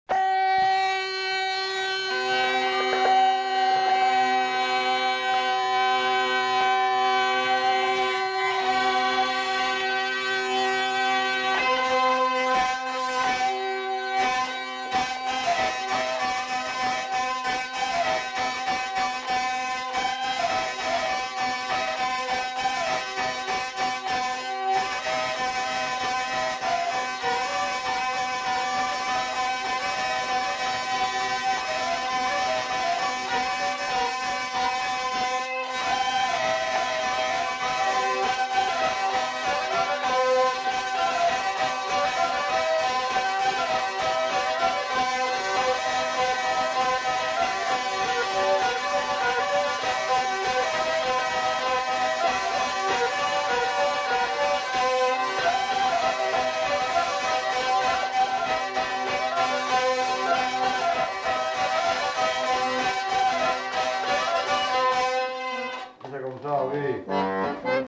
Aire culturelle : Gabardan
Lieu : Herré
Genre : morceau instrumental
Instrument de musique : vielle à roue
Danse : matelote